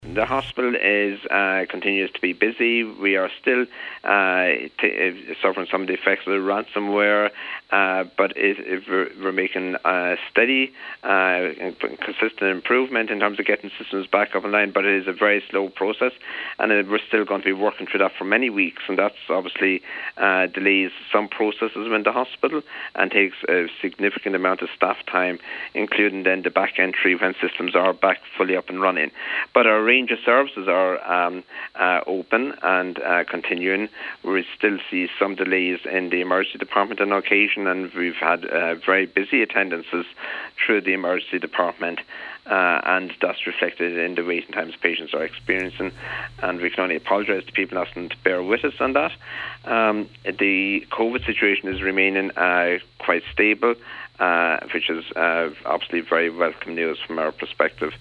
Speaking on today’s Nine til Noon Show